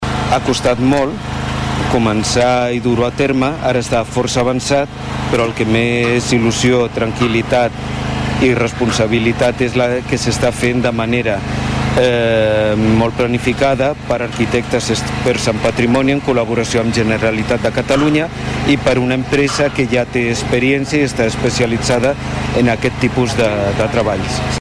Ho explica el regidor encarregat del projecte, Rafa Delgado,